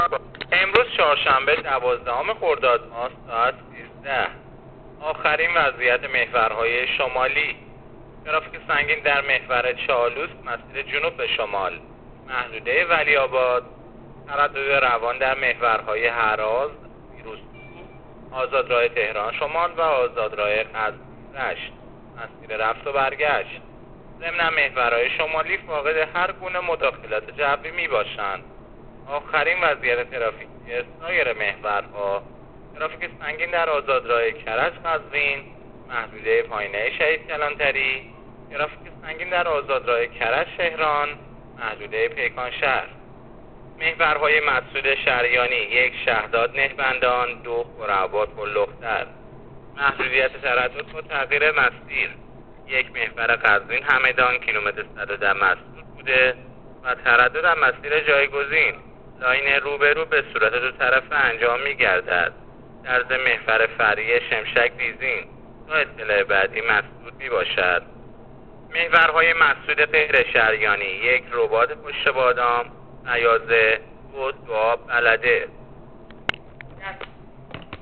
گزارش رادیو اینترنتی از آخرین وضعیت ترافیکی جاده‌ها تا ساعت۱۳ دوازدهم خرداد